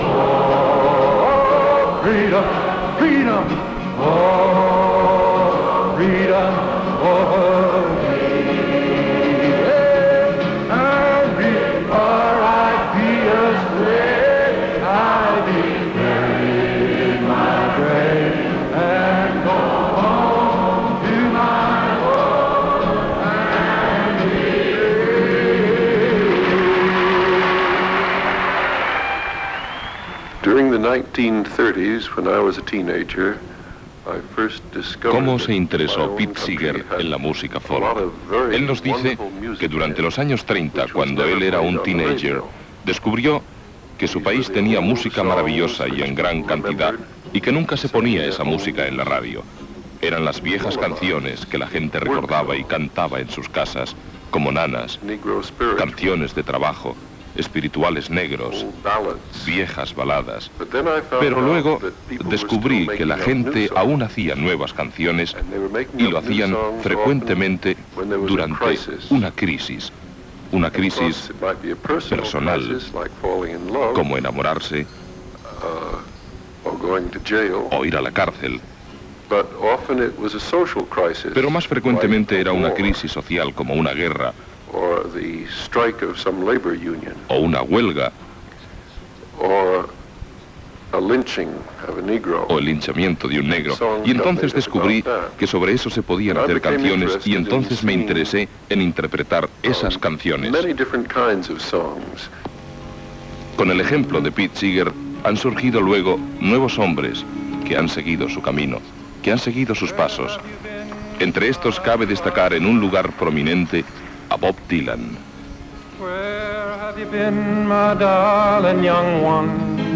Temes musicals i entrevista al cantant Pete Seeger que havia actuat a Espanya.
Musical